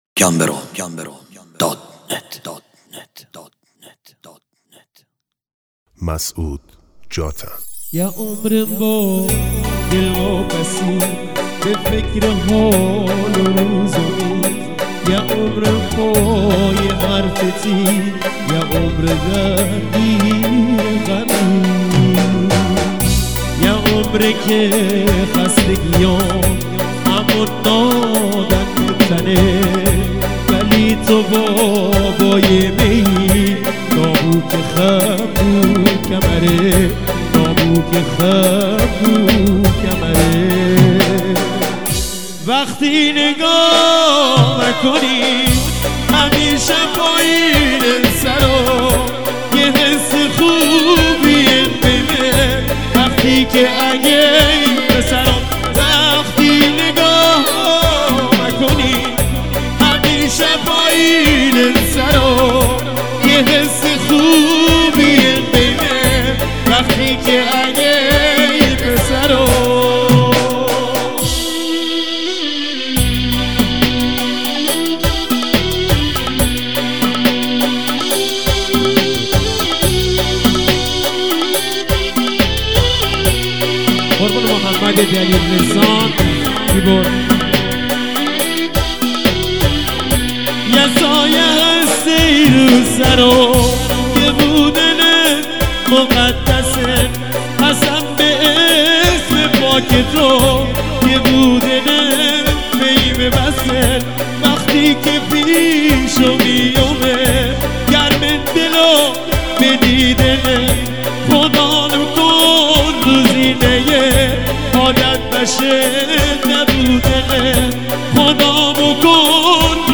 بستکی